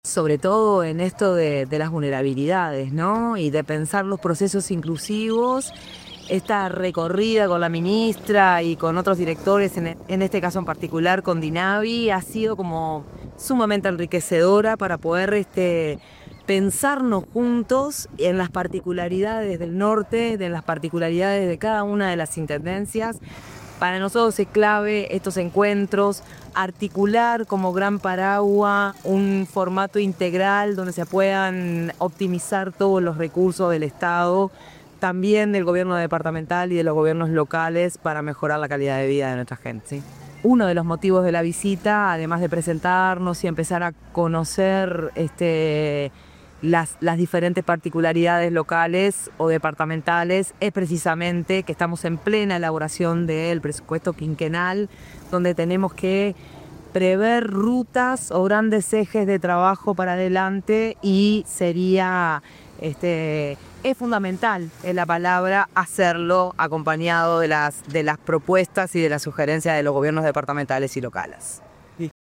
Declaraciones de la directora de Dinisu, Silvana Nieves
La directora nacional de Integración Social y Urbana (Dinisu), Silvana Nieves, realizó declaraciones durante su visita al departamento de Tacuarembó,